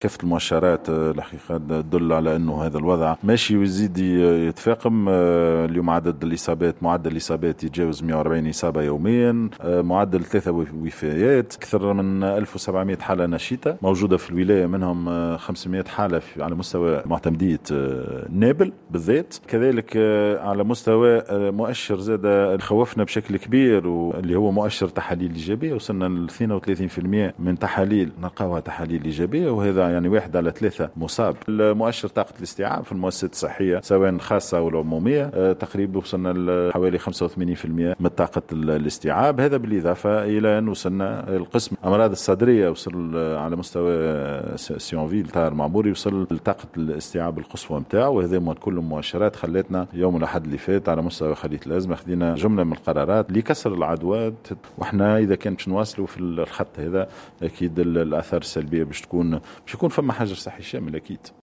واضاف في تصريح لمراسلة الجوهرة أف أم، أن المجلس الجهوي رصد بصفة استعجالية 150 الف دينار لاقتناء التجهيزات الطبية لفائدة المستشفى الجهوي الطاهر المعموري بنابل.